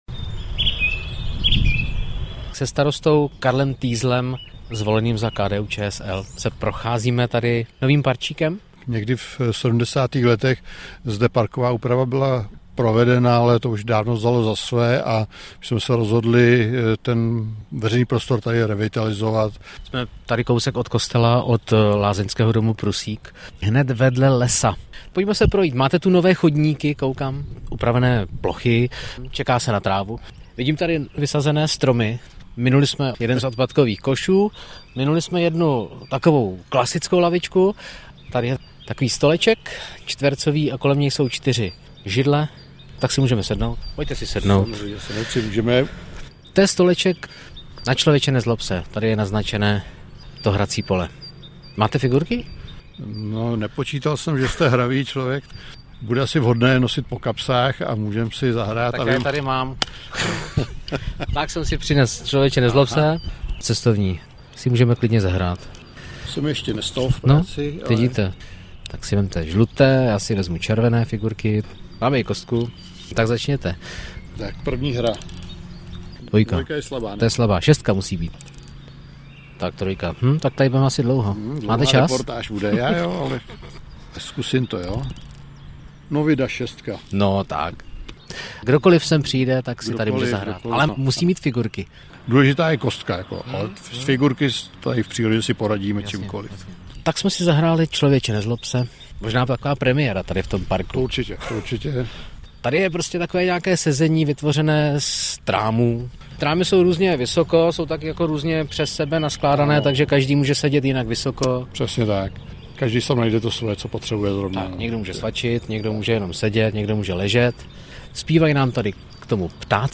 Zprávy pro Plzeňský kraj: V obnoveném parčíku v Konstantinových Lázních si zahrajete šachy i Člověče, nezlob se!